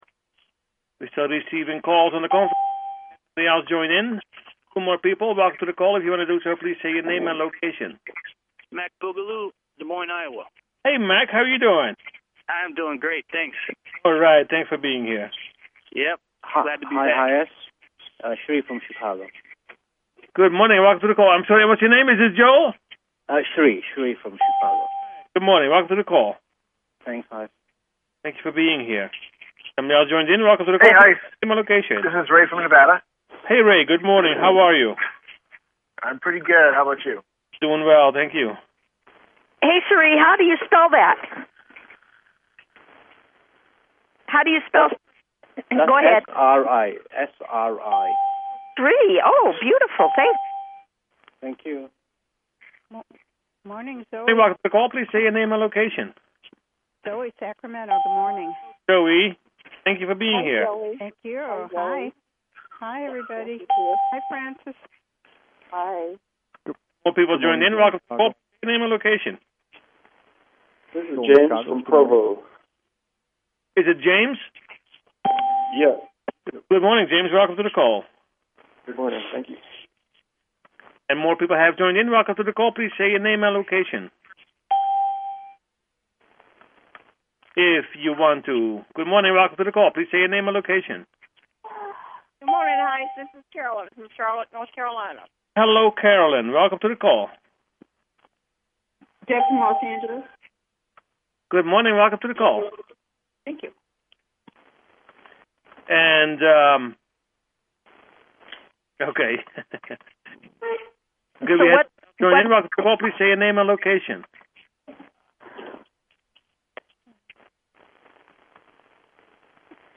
Meditation
Talk Show